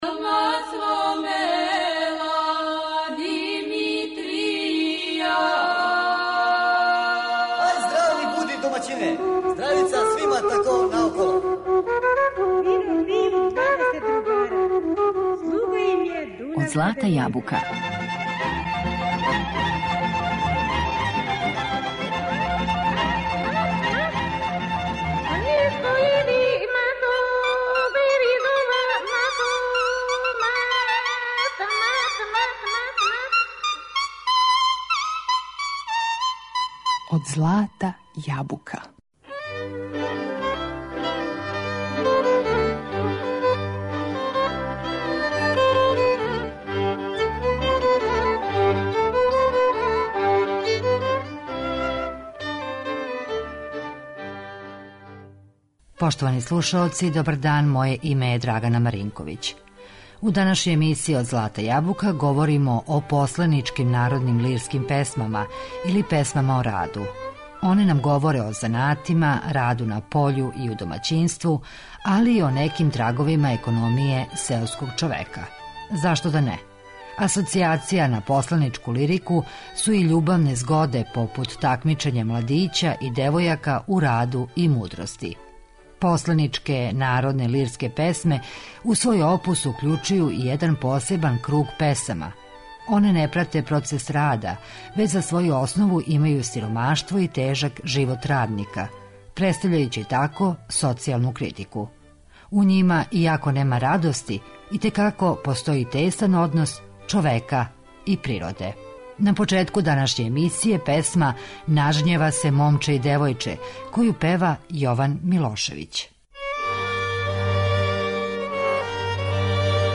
Данашњу емисију посветили смо посленичким лирским народним песмама, или песмама које су везане за рад.